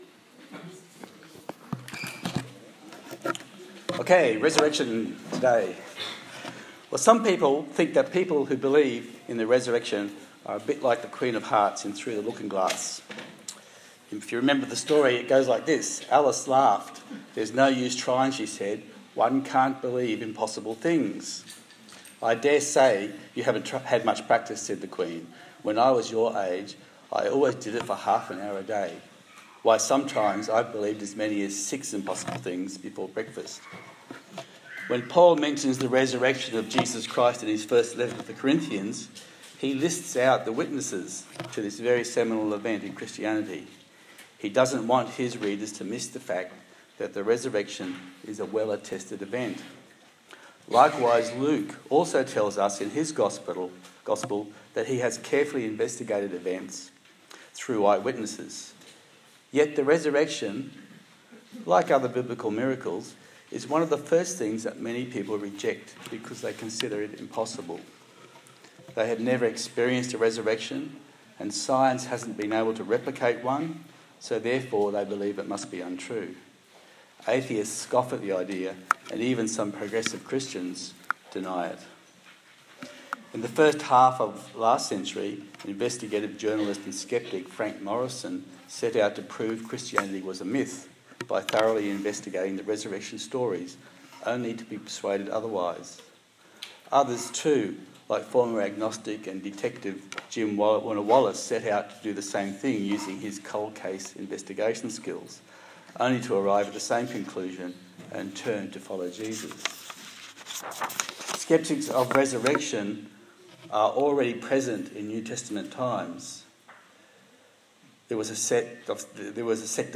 Service Type: Easter Sunday